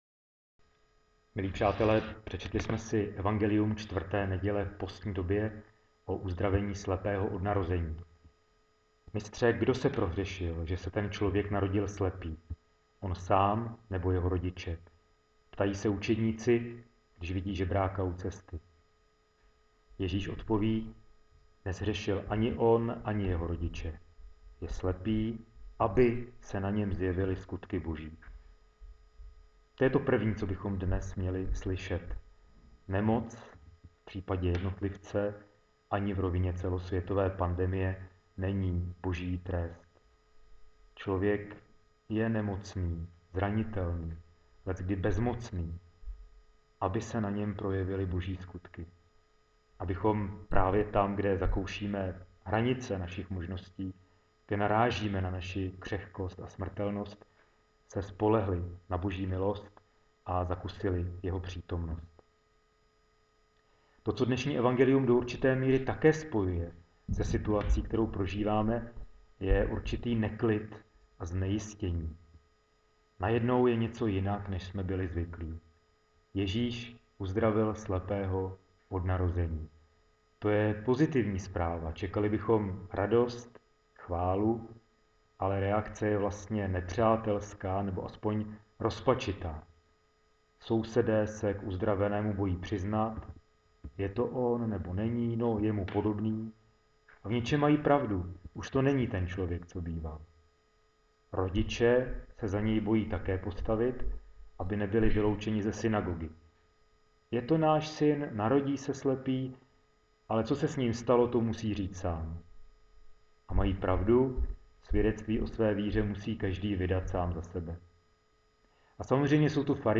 Jan 9 kazani.wma